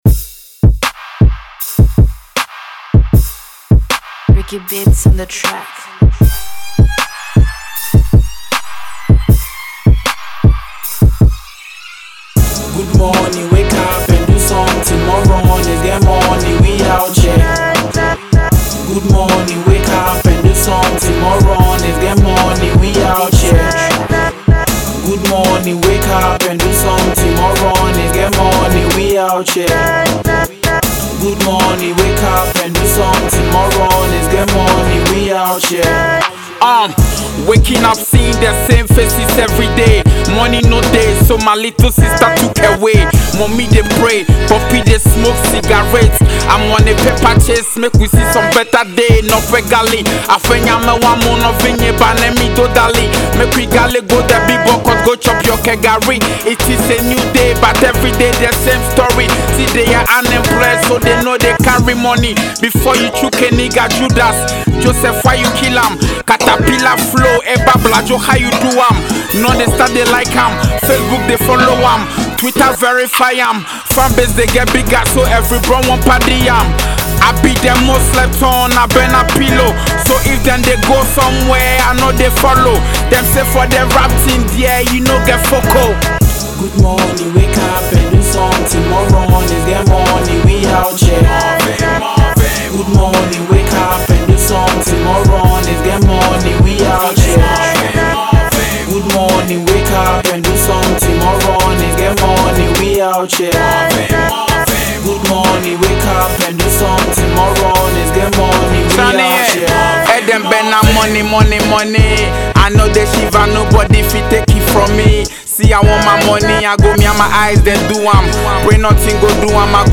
indigenous Hip-Hop